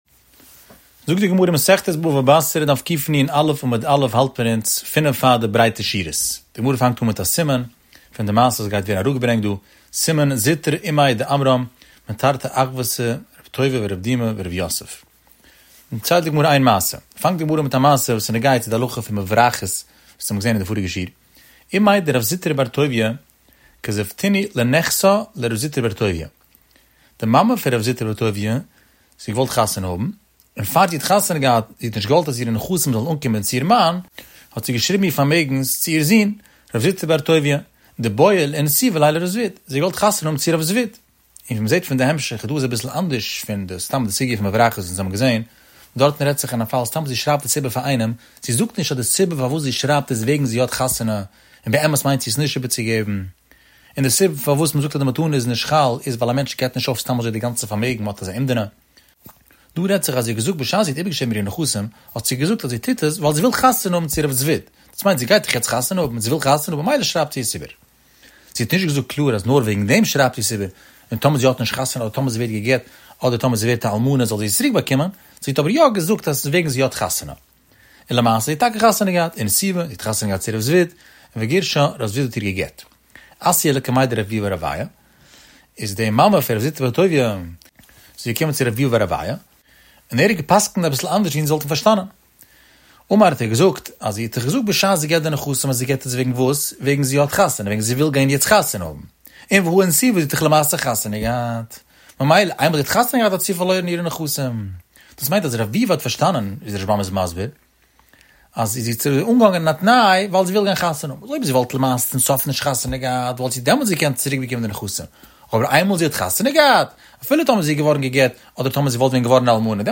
The daily daf shiur has over 15,000 daily listeners.